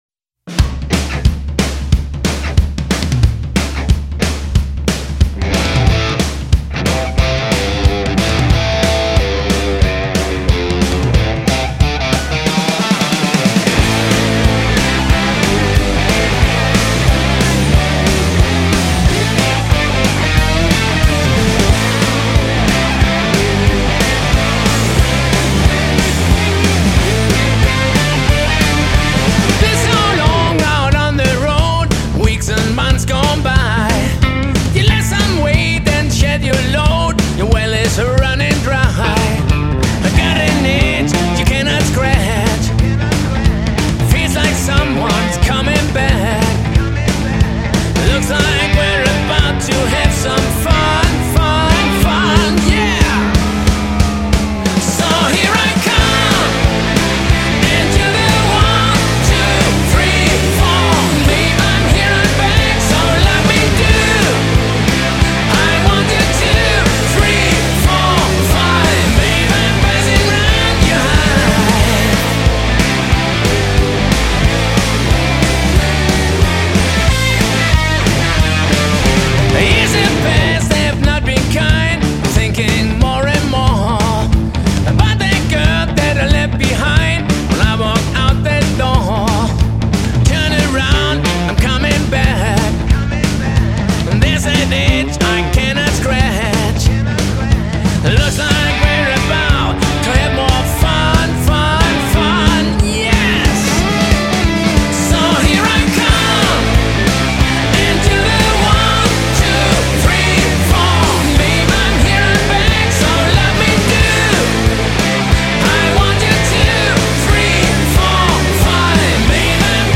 скачать rock